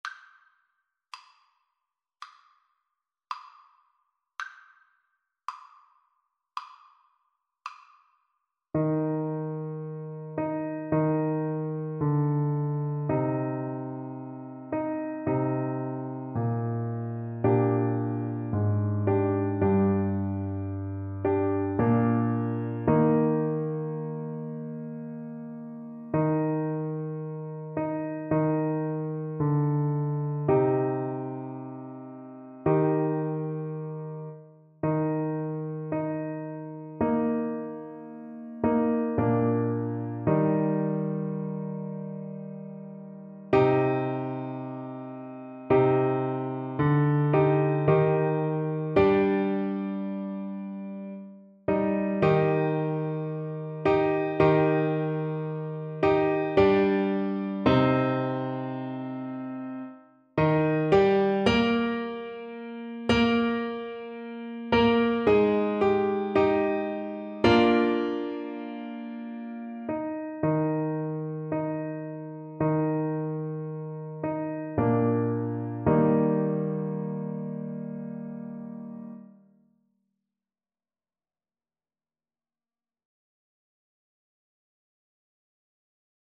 Clarinet
Steal Away is a spiritual from the African American tradition,
Andante
4/4 (View more 4/4 Music)
Arrangement for Clarinet and Piano
Eb major (Sounding Pitch) F major (Clarinet in Bb) (View more Eb major Music for Clarinet )